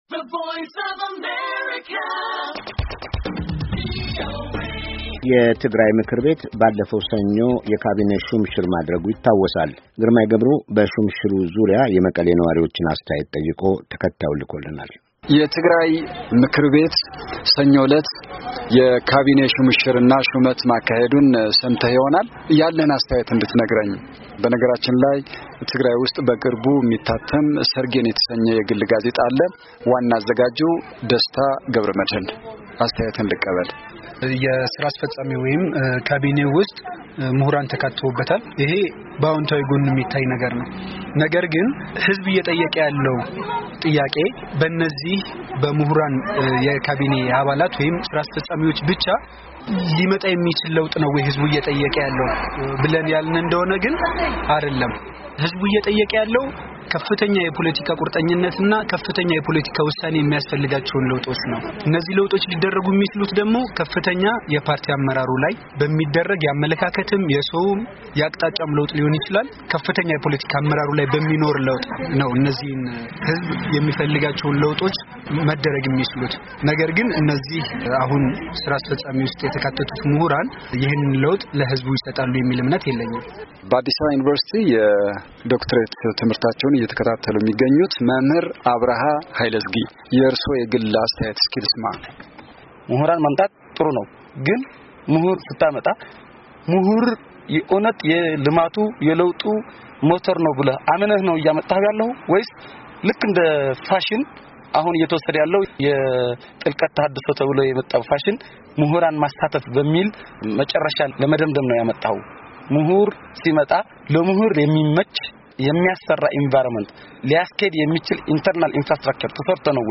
የትግራይ ምክር ቤት የካቤኔ ሹም ሽር ማድረጉን በማስመልከት የመቀሌ ኗሪዎች የሠጡት አስተያየት